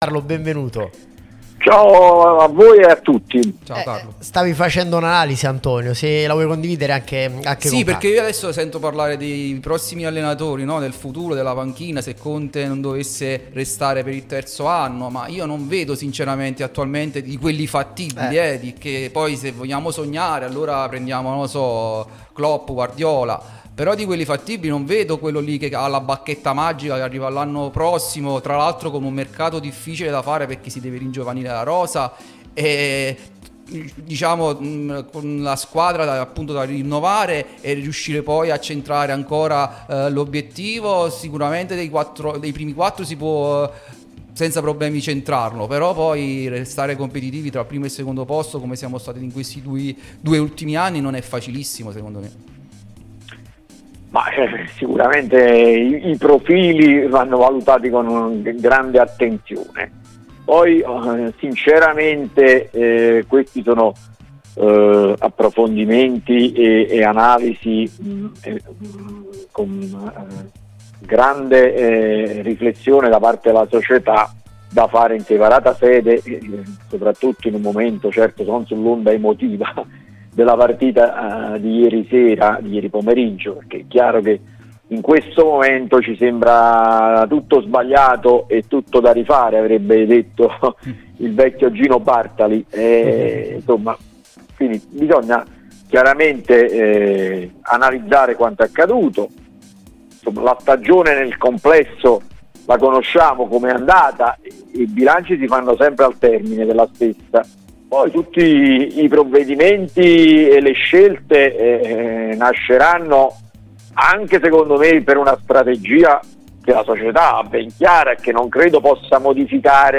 giornalista